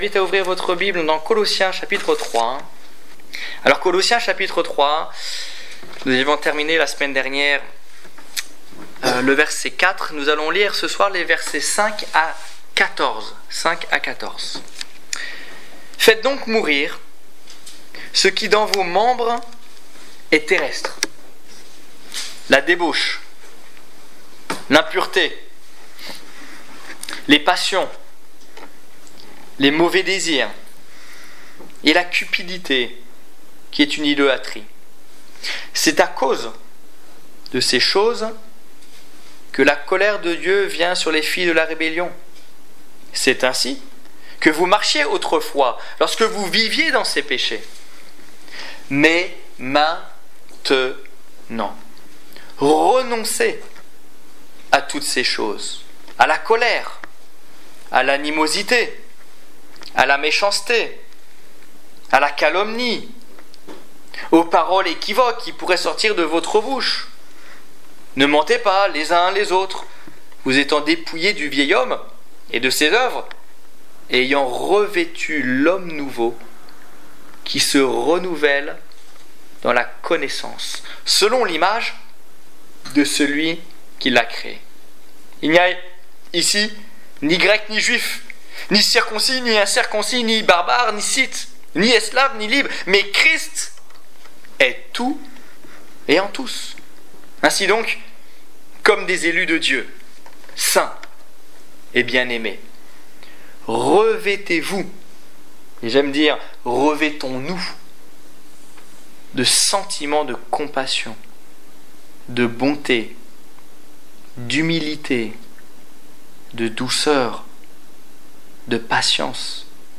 Étude biblique du 19 novembre 2014